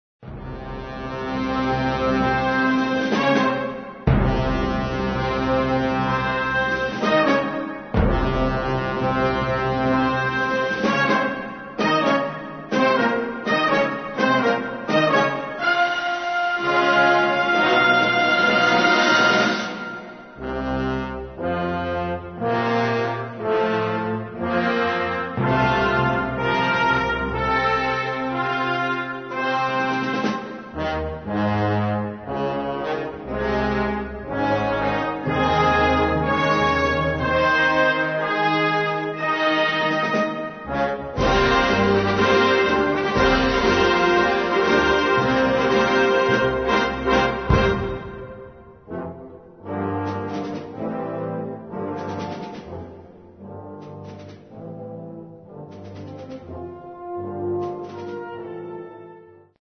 Kategorie Blasorchester/HaFaBra
Unterkategorie Konzertmusik